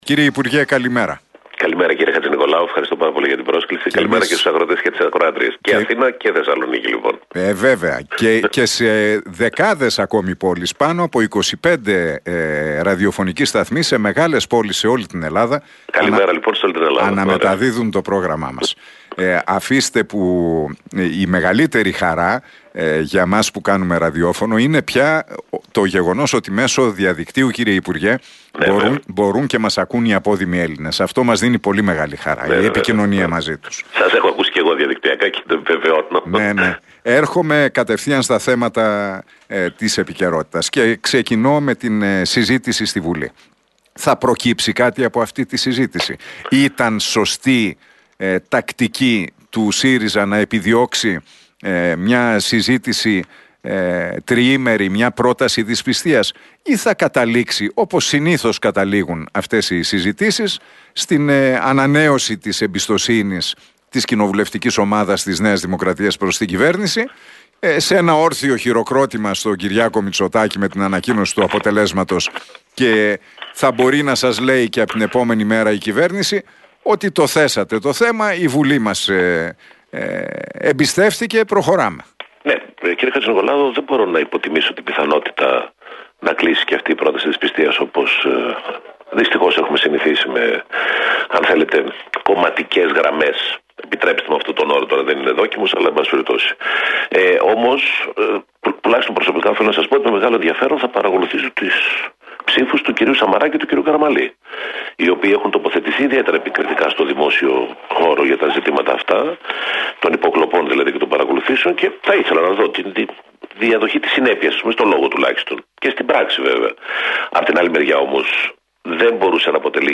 Ο βουλευτής και τομεάρχης Περιβάλλοντος και Ενέργειας του ΣΥΡΙΖΑ, Σωκράτης Φάμελλος, δήλωσε στον Realfm 97,8 και στην εκπομπή του Νίκου Χατζηνικολάου για την